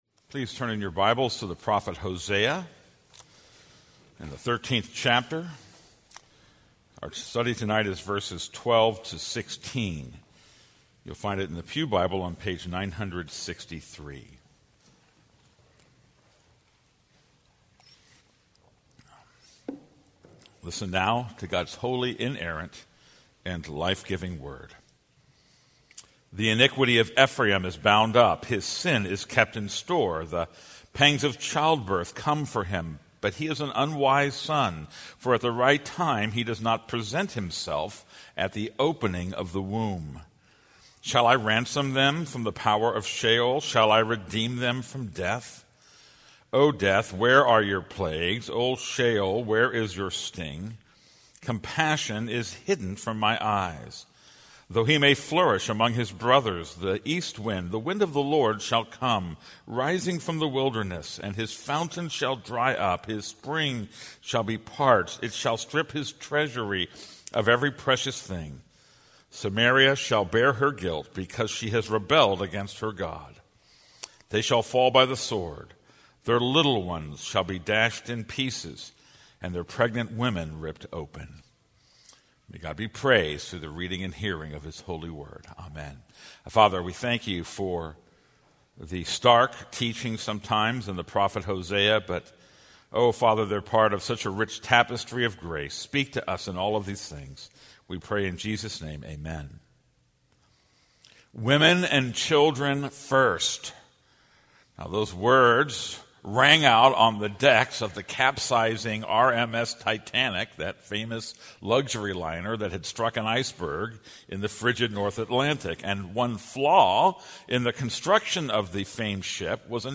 This is a sermon on Hosea 13:12-16.